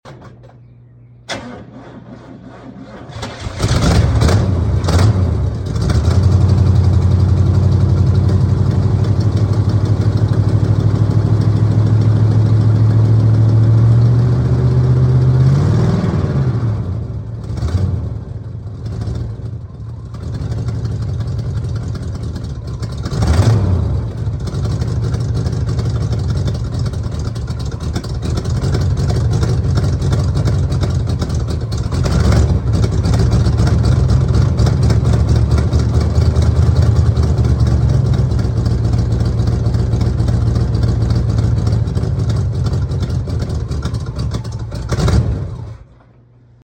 Lil cold start with the sound effects free download
Lil cold start with the glass packs hasnt been started in a minute so battery but it managed to start it right up